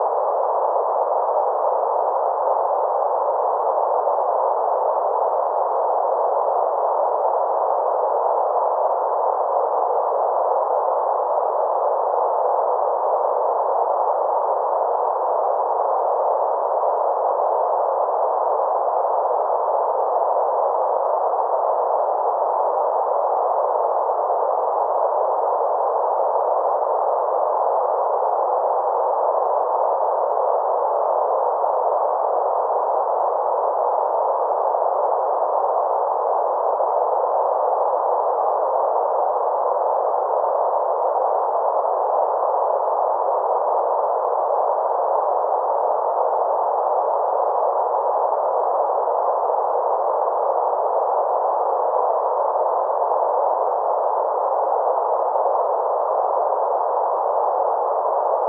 Band-Limited-Pink-Noise-500Hz-1kHz.wav